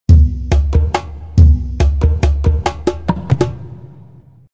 С большой буквы я написал барабаны, которые будут делать основные акценты.
Для этой ритмической фигуры я выбрал темп 140 ударов в минуту.
Этнический ритм
zz_les03_ethnic_loop01.mp3